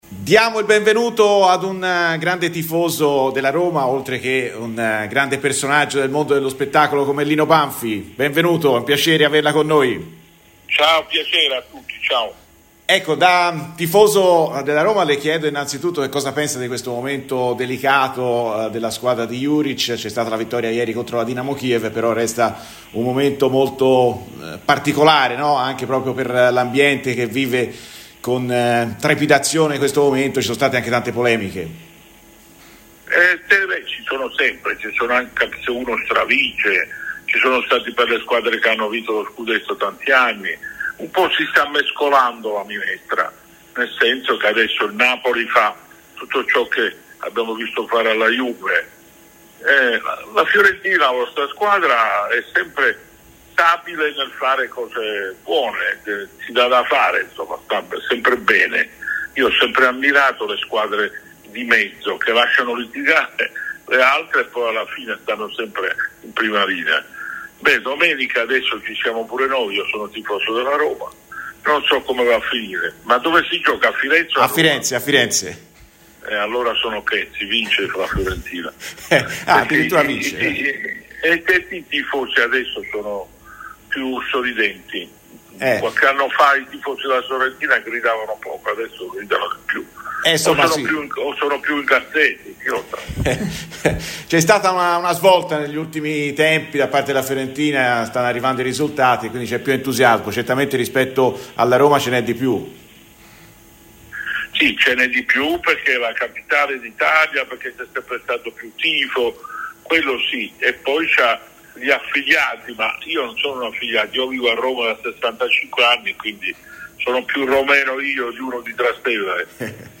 Il noto attore e tifoso della Roma Lino Banfi ha parlato oggi a Radio Firenzeviola, durante "Viola amore mio", verso Fiorentina-Roma: "Le polemiche ci sono sempre, anche se uno stravince e vince lo Scudetto.